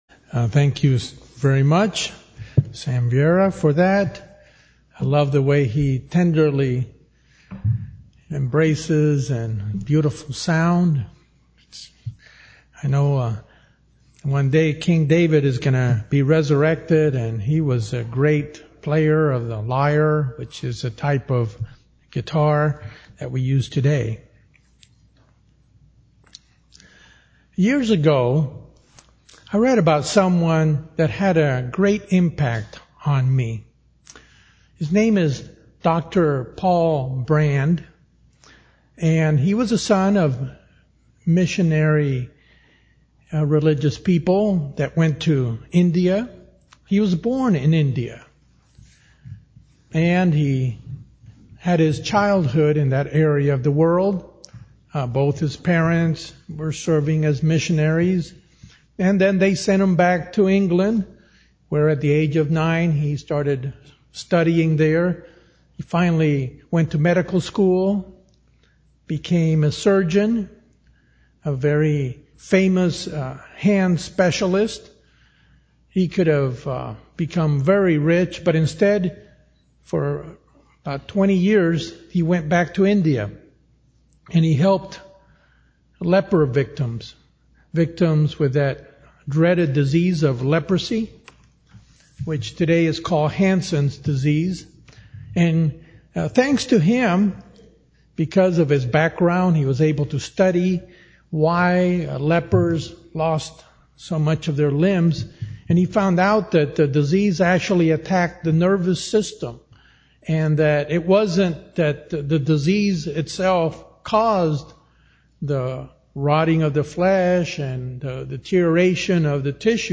This sermon shows the analogy between the human body and the Body of Christ as the Church fit so well.